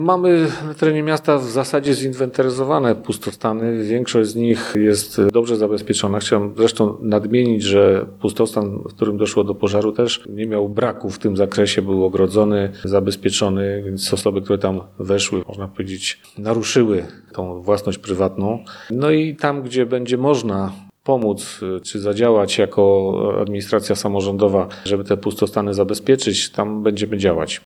W Ełku pustostany zostały już zinwentaryzowane. Kontrolowany jest stan ich zabezpieczenia – mówi Mirosław Hołubowicz – zastępca prezydenta Ełku.